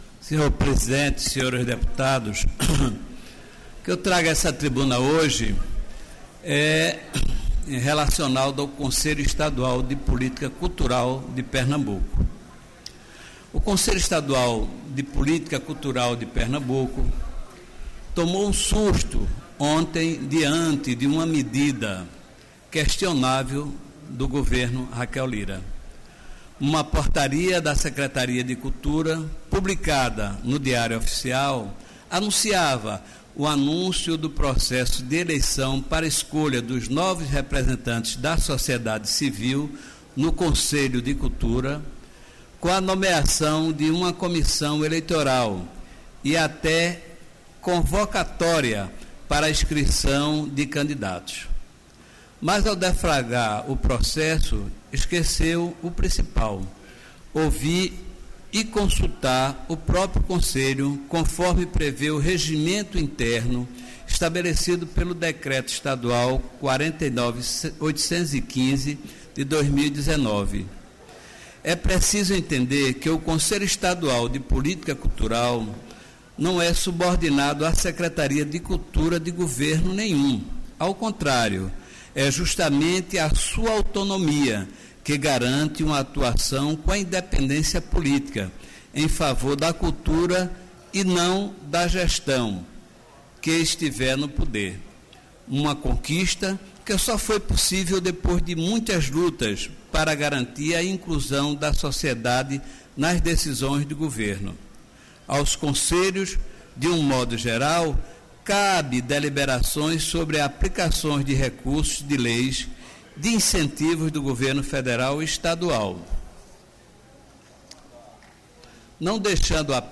Ouça os pronunciamentos dos deputados em Plenário